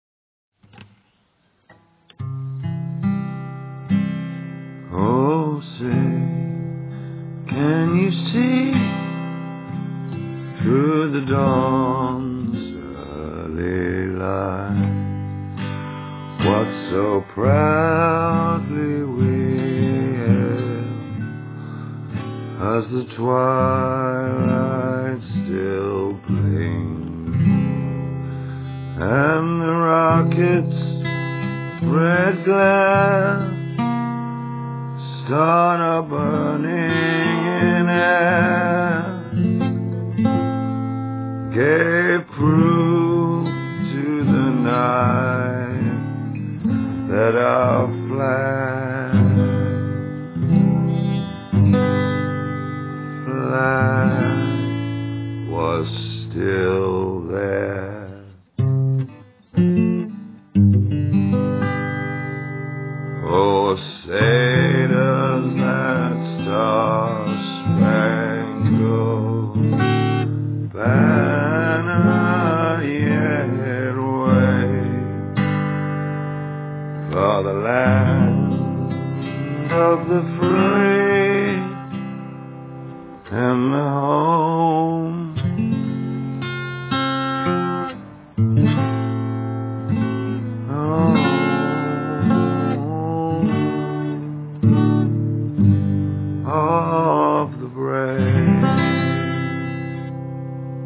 half take… (T.Monk or didnt know chords?? clean voice rec … no echo/reverb !!!